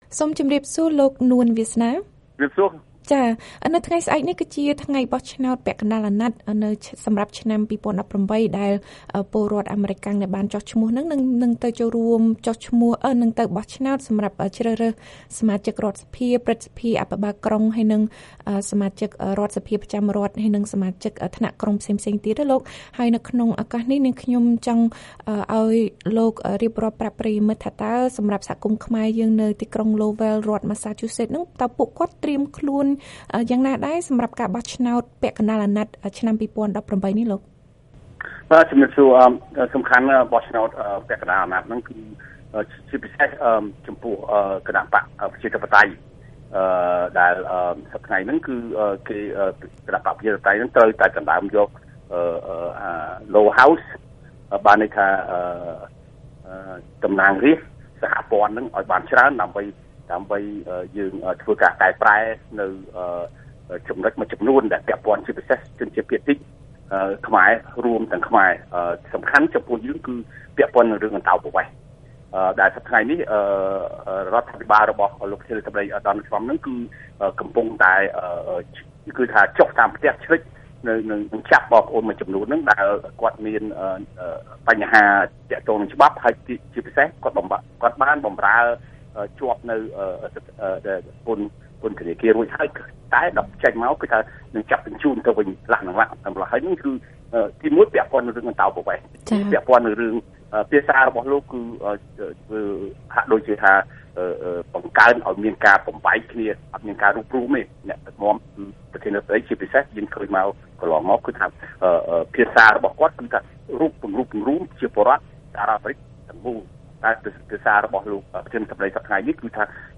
បទសម្ភាសន៍ VOA៖ បញ្ហាអន្តោប្រវេសន៍ស្ថិតក្នុងចិត្តអ្នកបោះឆ្នោតខ្មែរសញ្ជាតិអាមេរិកាំងនៅទីក្រុងឡូវែល